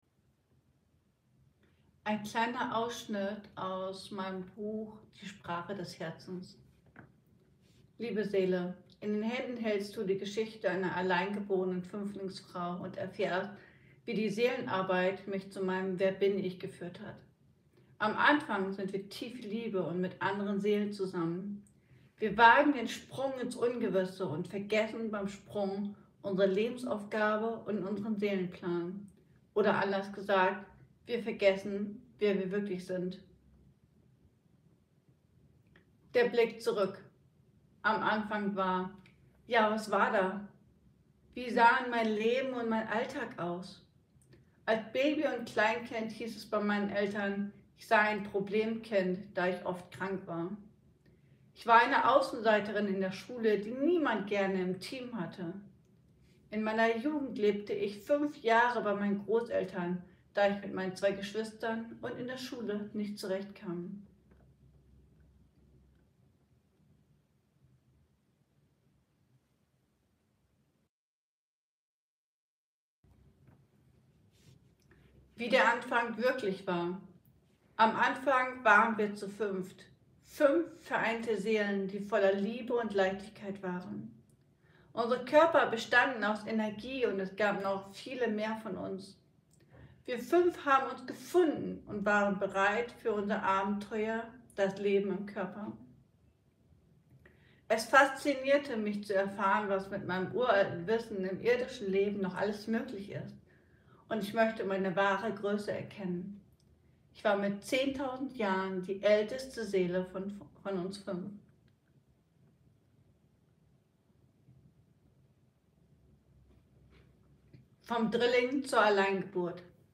Die Sprache des Herzens - Lesungsausschnitt